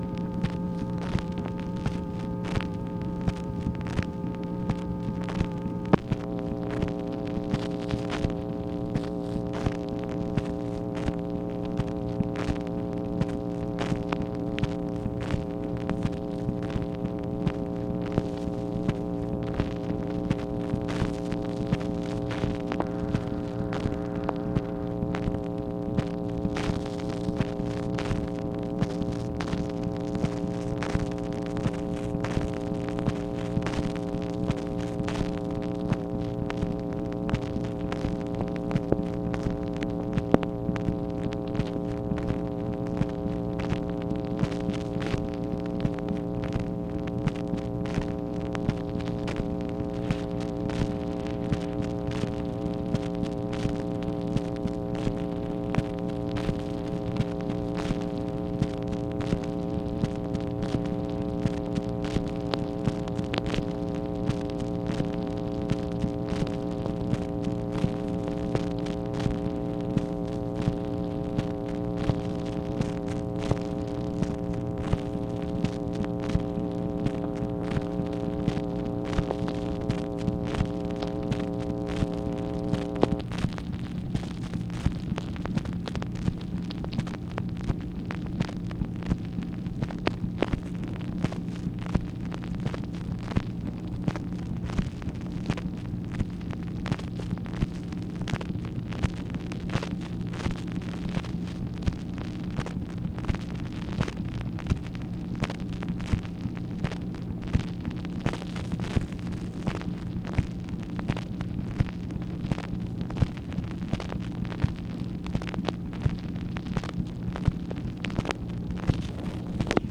MACHINE NOISE, May 18, 1965
Secret White House Tapes | Lyndon B. Johnson Presidency